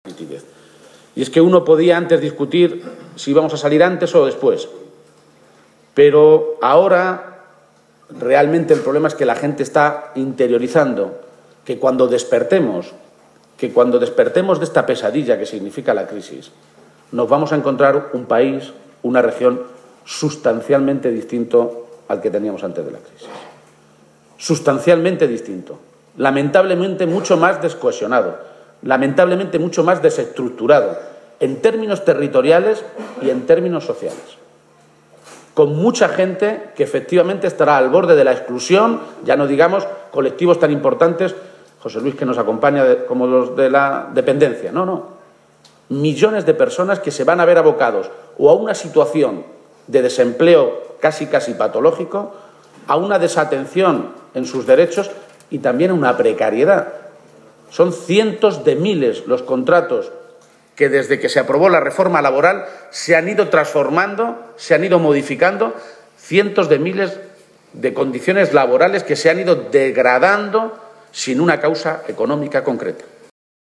García-Page ha hecho estas consideraciones durante su intervención en el acto inaugural del VI Congreso Regional del sindicato UGT celebrado en Toledo, donde el líder de los socialistas castellano-manchegos ha afirmado que todos los indicadores sobre la situación actual de la Región «reflejan una realidad verdaderamente grave en Castilla-La Mancha», tras la política de recortes de los gobiernos de Cospedal y Rajoy.